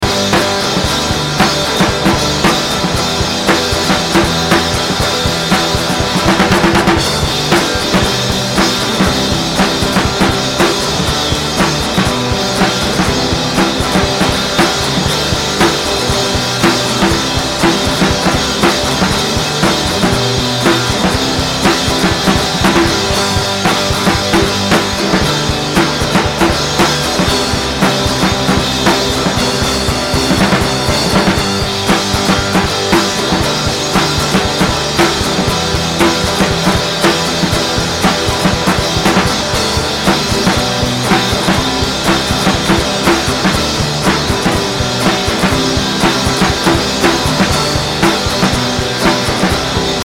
Indie Rock, Indie Pop >
Post Rock, Experimental Rock >